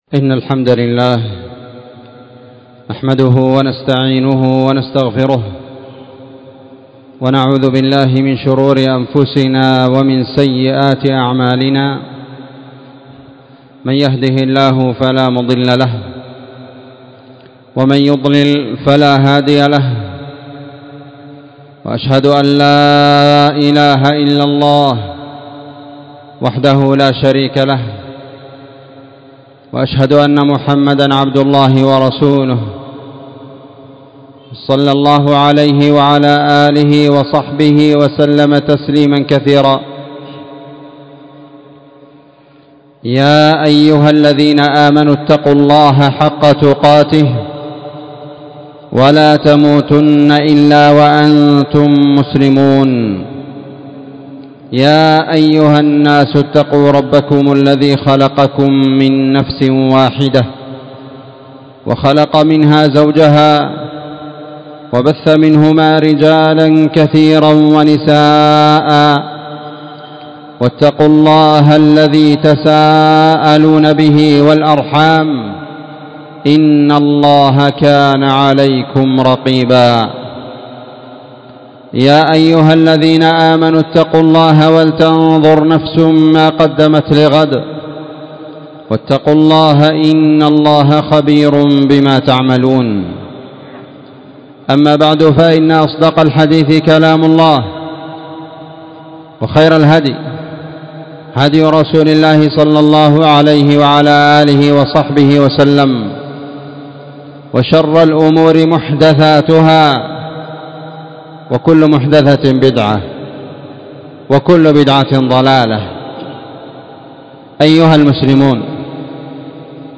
خطبة جمعة
في مسجد المجاهد- النسيرية- تعز